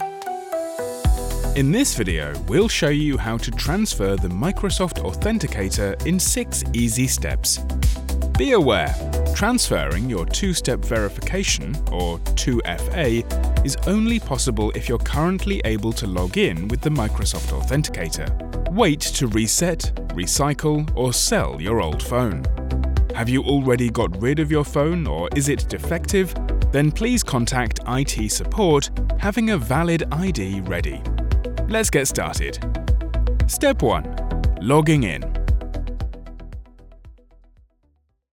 English (British)
E-learning
Custom-built home studio